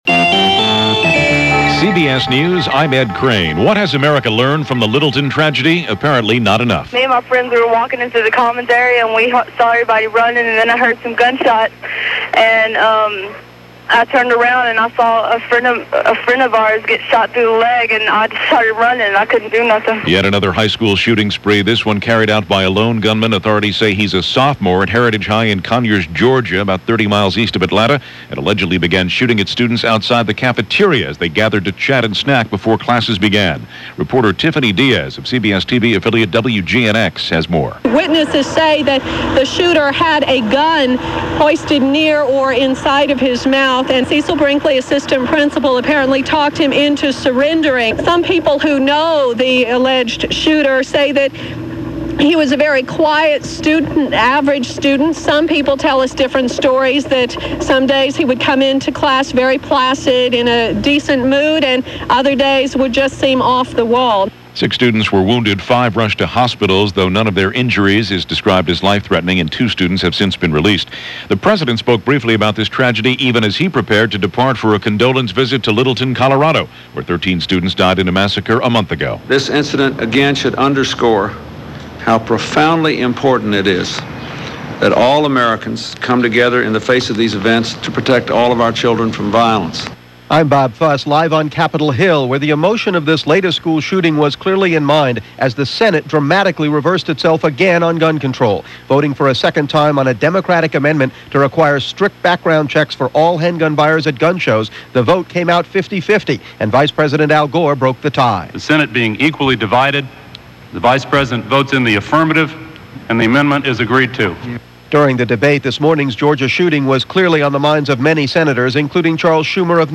School Shooting Deja-Vu - Columbine Copycats - May 20, 1999 - news of another high school shooting - CBS Radio News.